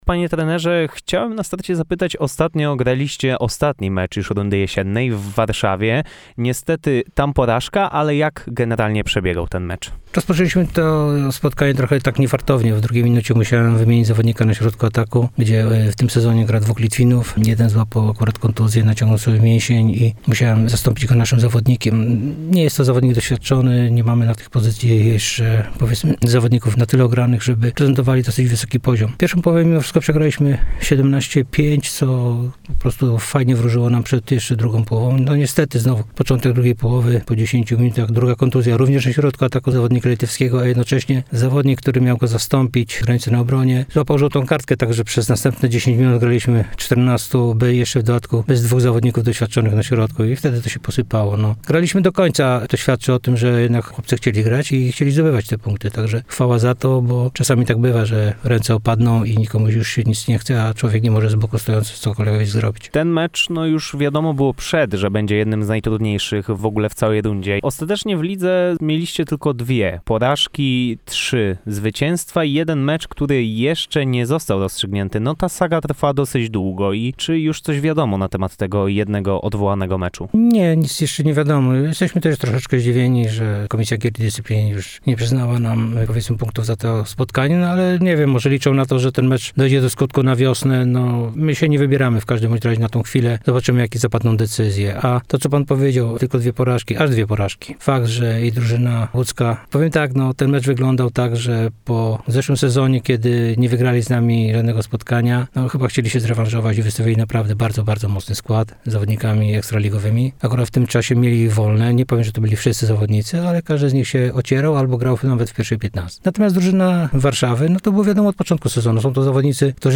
Posłuchajcie, jak podsumował ostatnie miesiące.